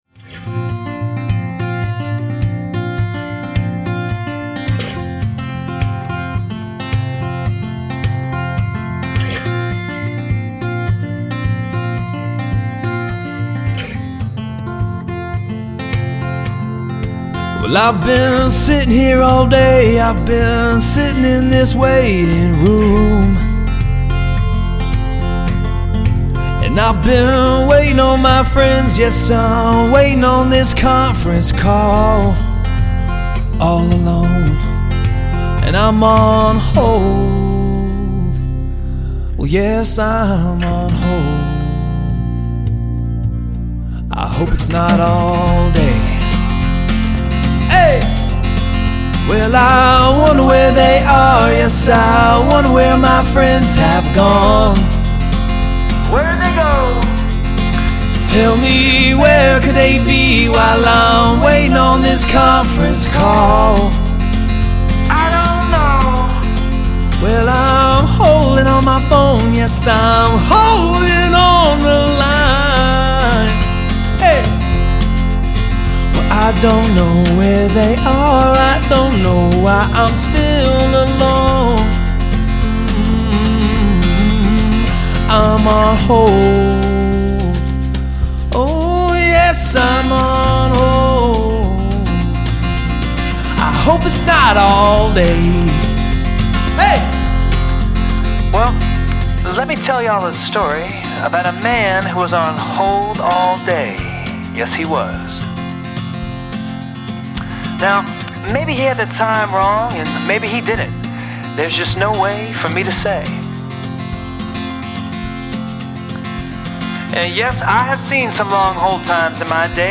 Best hold music ever…
Im-On-Hold.mp3